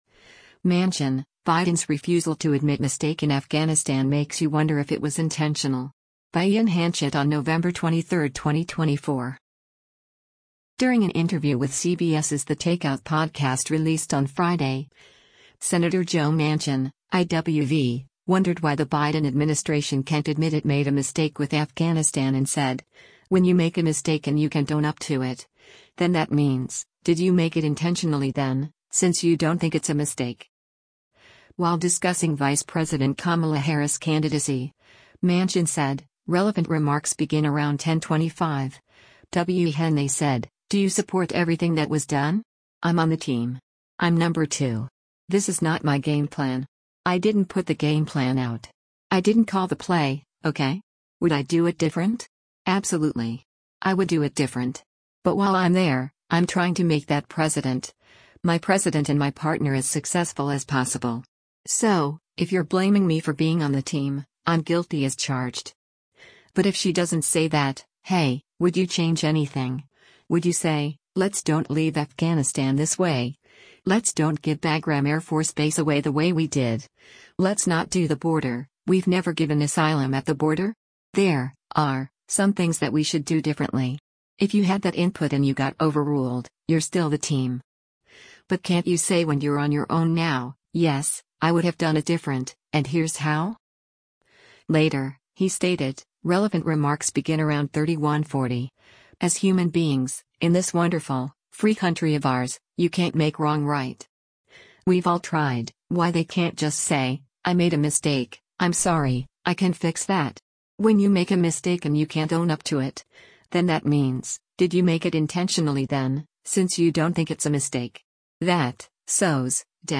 During an interview with CBS’s “The Takeout” podcast released on Friday, Sen. Joe Manchin (I-WV) wondered why the Biden administration can’t admit it made a mistake with Afghanistan and said, “When you make a mistake and you can’t own up to it, then that means, did you make it intentionally then, since you don’t think it’s a mistake?”